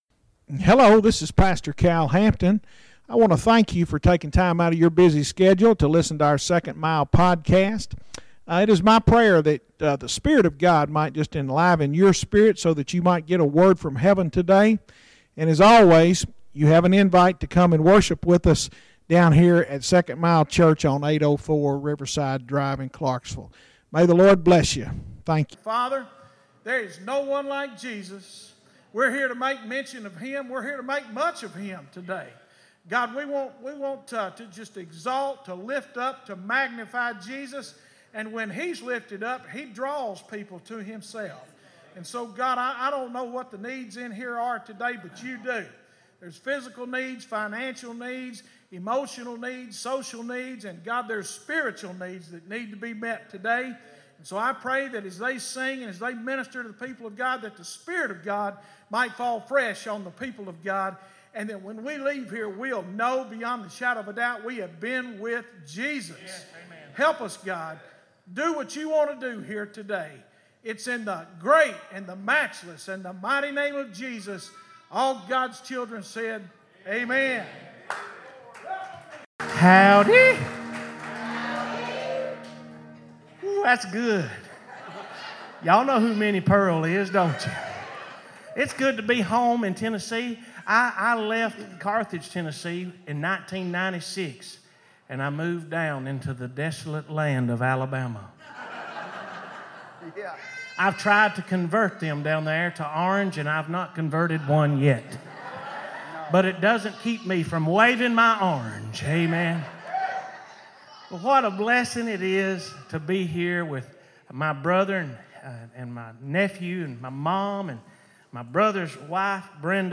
Bible Study “Anger”